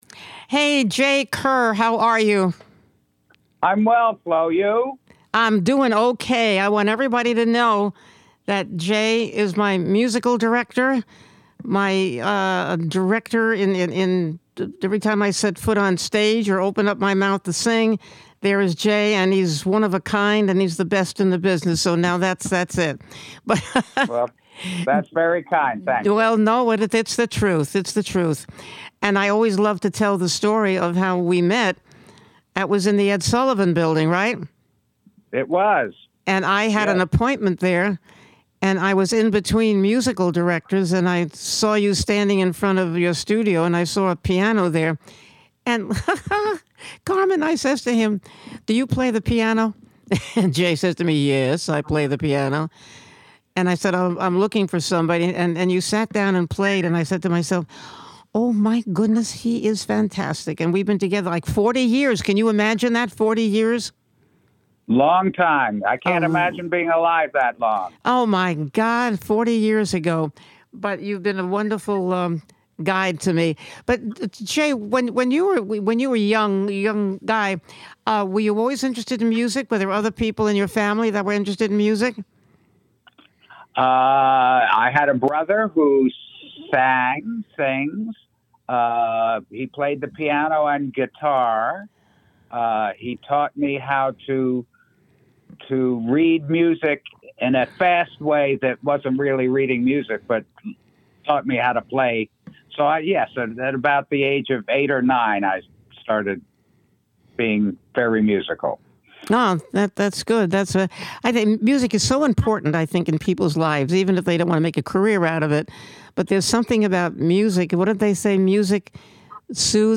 With a wicked wit and a true heart she banters with fellow singers and thespians, local luminaries and mover/shakers and knows how to get them to reveal what makes them tick.